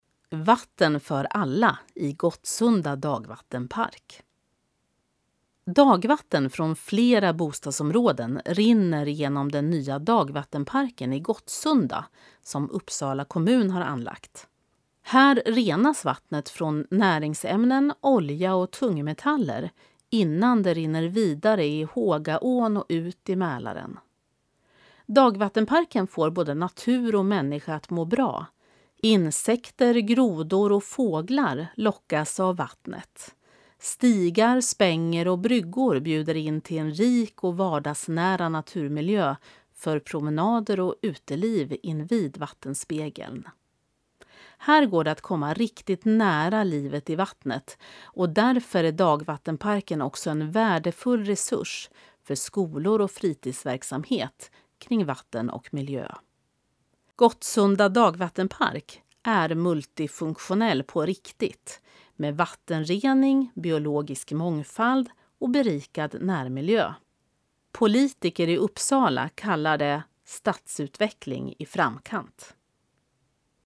Klicka här för att lyssna till texten, inläst av en professionell uppläsare